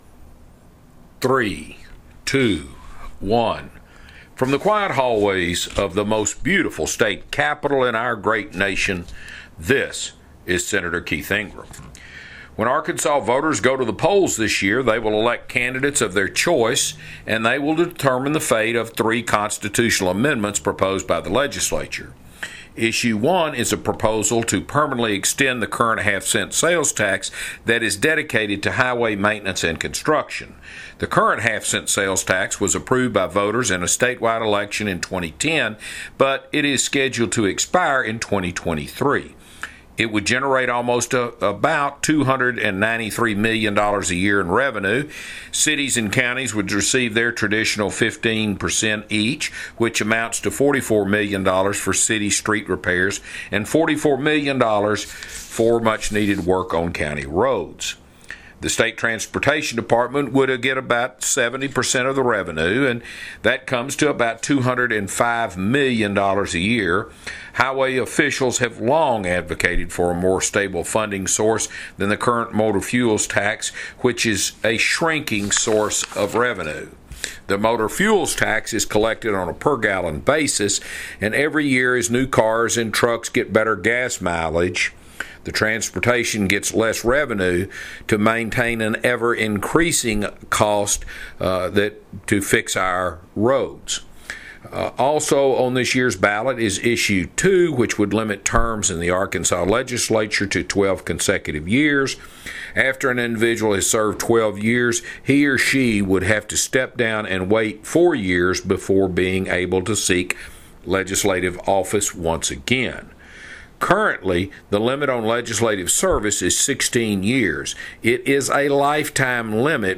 Weekly Address – October 9, 2020 | 2020-10-09T14:56:04.111Z | Sen.